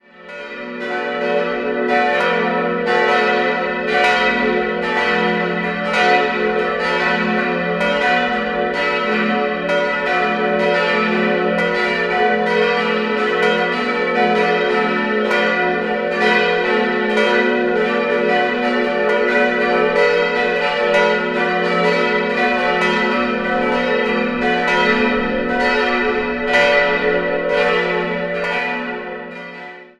Idealquartett: fis'-a'-h'-d'' Die Glocken wurden 1980 von der Gießerei Bachert in Bad Friedrichshall gegossen.